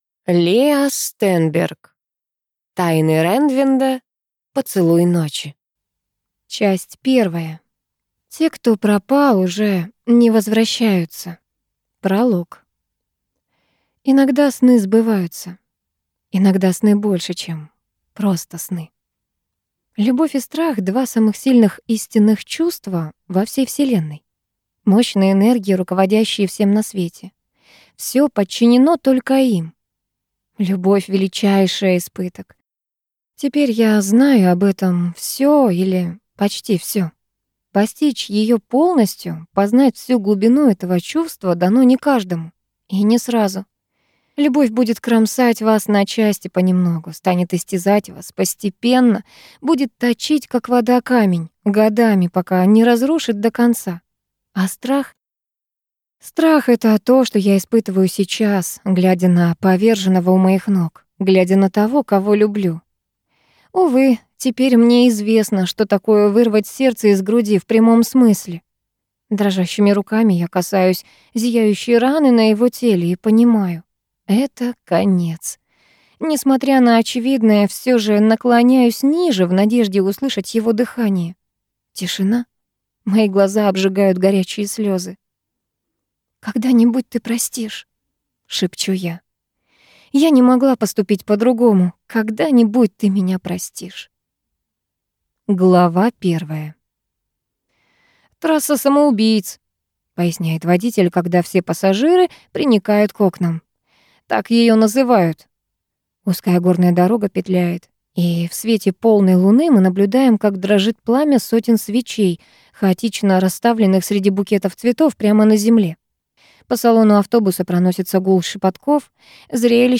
Аудиокнига Тайны Реннвинда. Поцелуй ночи | Библиотека аудиокниг